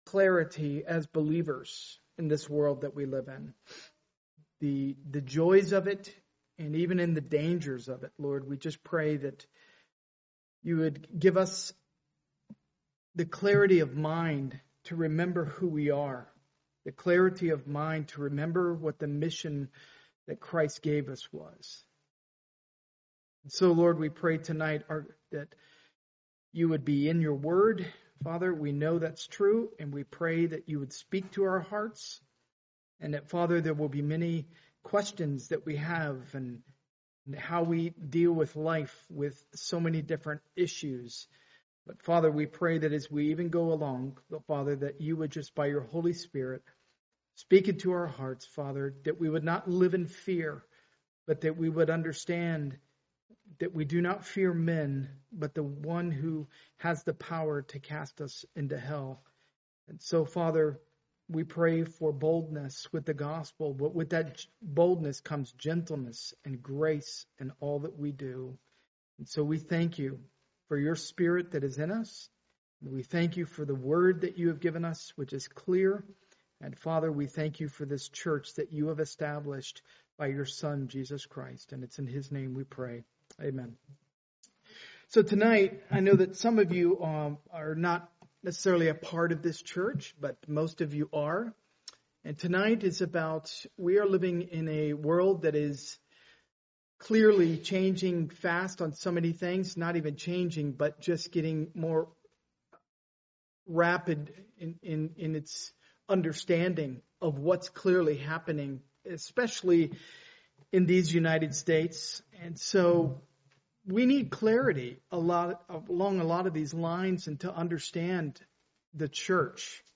Part of the The Book of Exodus series, preached at a Morning Service service.
Sermon